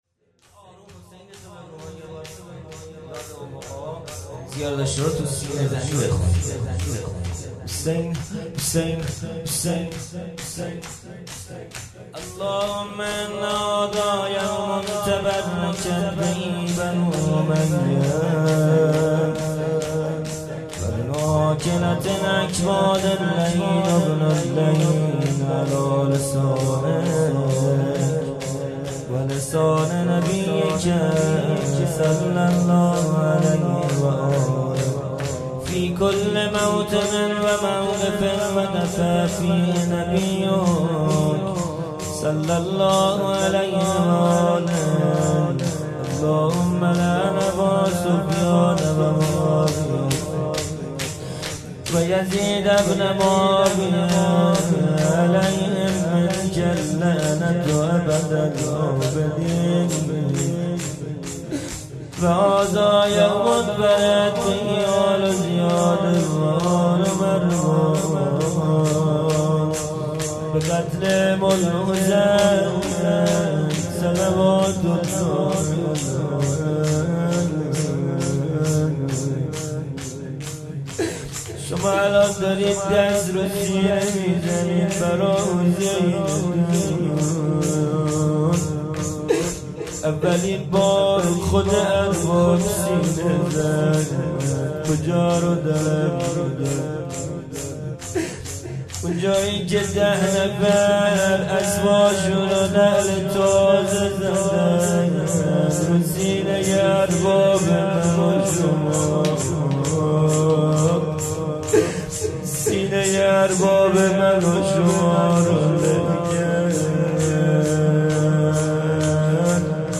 خیمه گاه - هیئت بچه های فاطمه (س) - شور و زیارت عاشورا
جلسۀ هفتگی